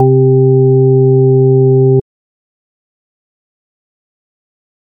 Organ (6).wav